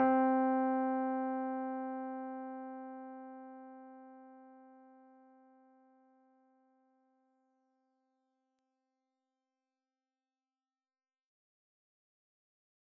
Organ (C).wav